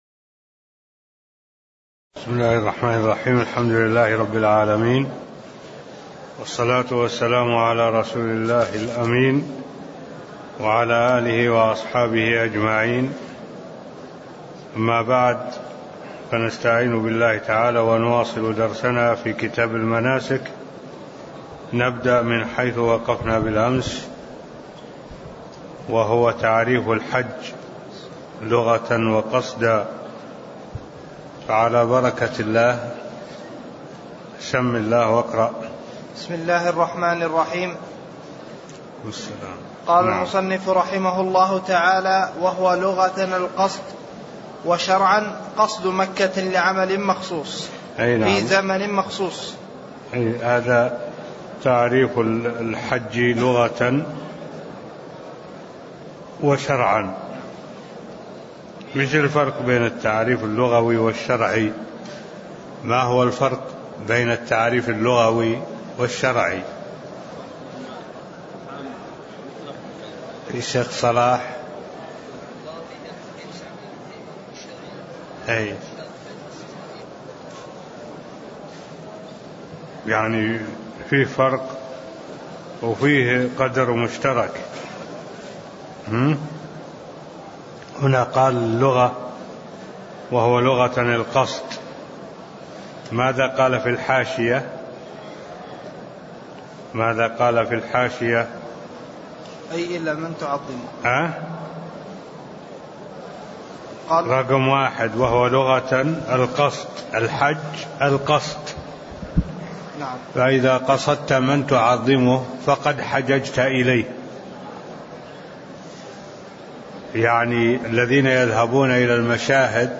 تاريخ النشر ٨ شوال ١٤٢٧ هـ المكان: المسجد النبوي الشيخ: معالي الشيخ الدكتور صالح بن عبد الله العبود معالي الشيخ الدكتور صالح بن عبد الله العبود تعريف الحج لغة وقصدا (002)